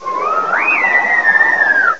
cry_not_tapu_fini.aif